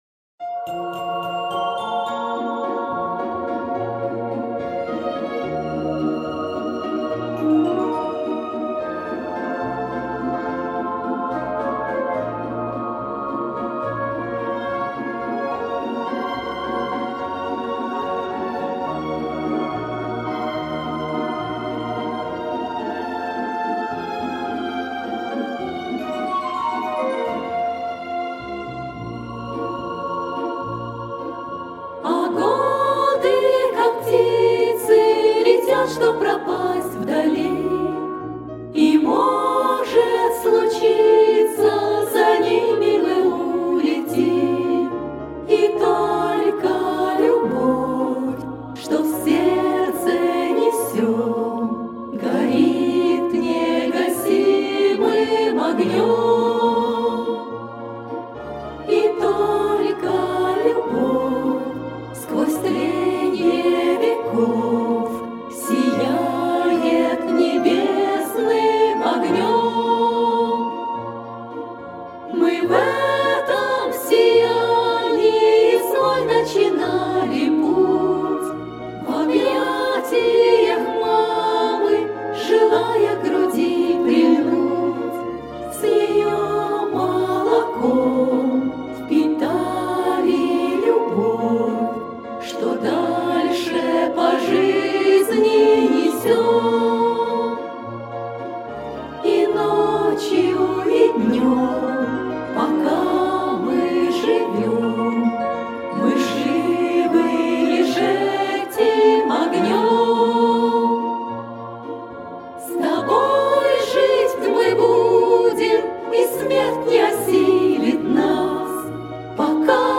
кавер-версия
Песни у костра